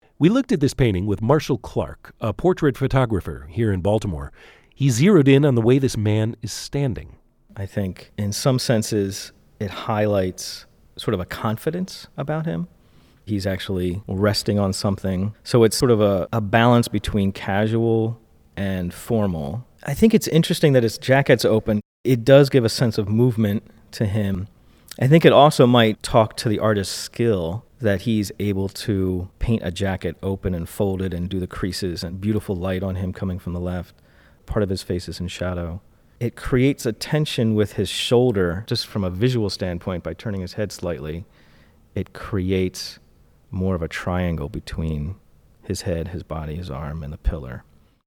A-portrait-photographers-view-2-1.mp3